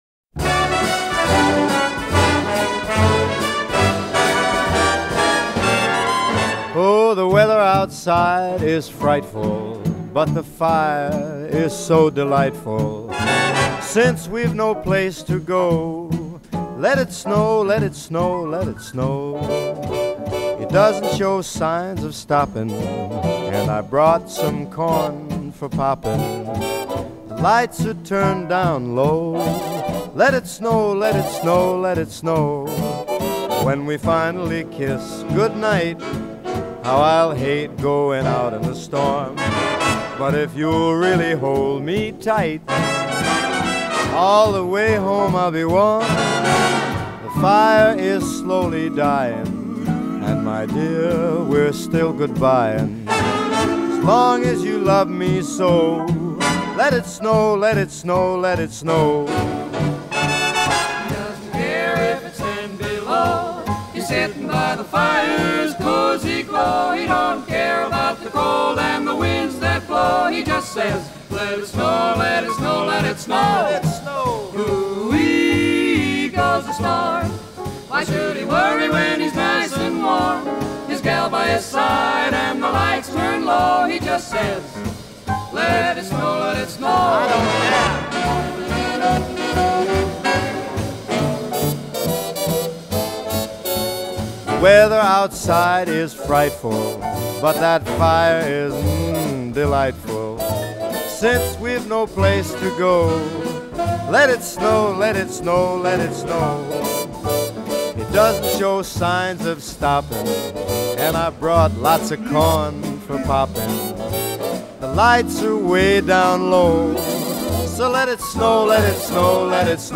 BPM160
Audio QualityCut From Video